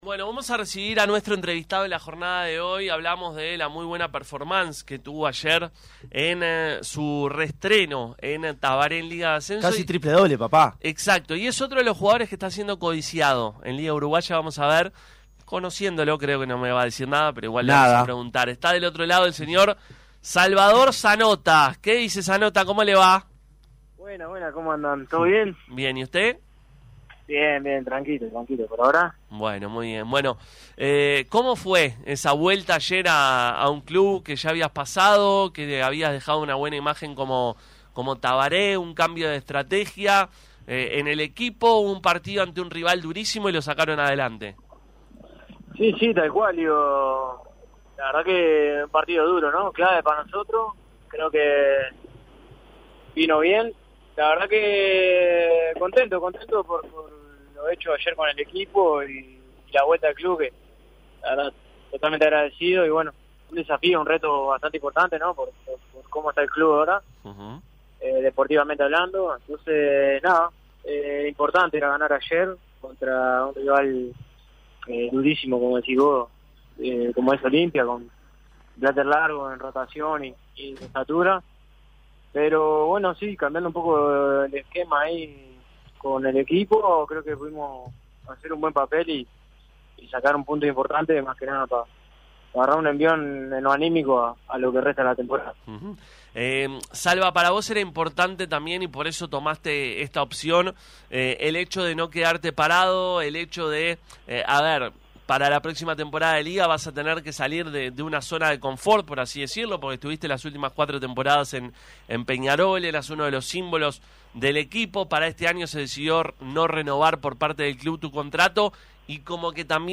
Hoy en Pica La Naranja dialogó con nosotros y esto nos decía: